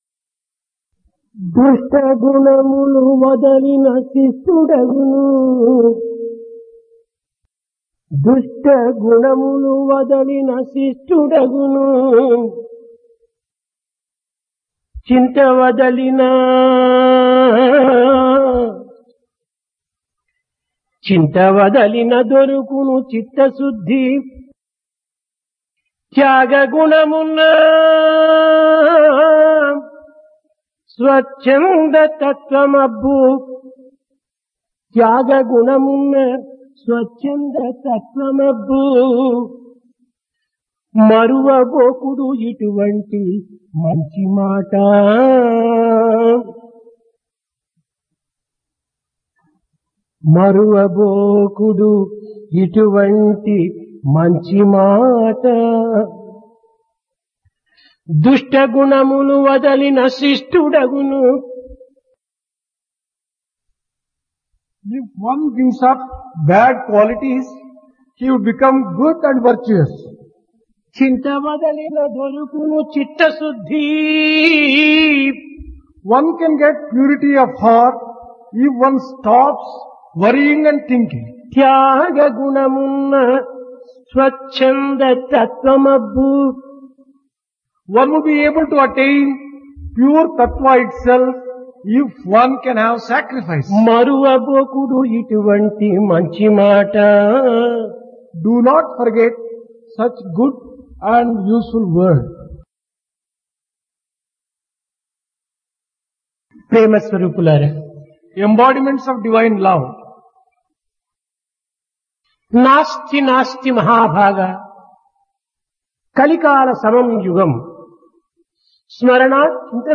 Dasara - Divine Discourse | Sri Sathya Sai Speaks
Place Prasanthi Nilayam Occasion Dasara